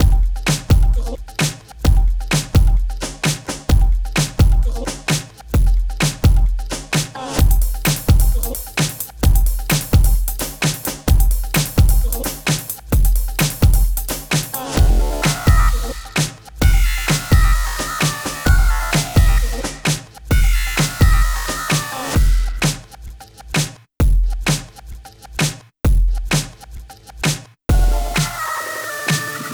Plus a couple drum/etc loops (44K/16bit, happy octatrack to those that celebrate)